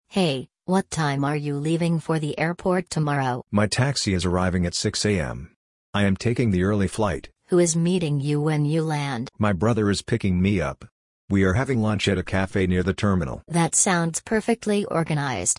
🎧 Shadowing: Checking the Schedule
Shadowing-lesson-12.mp3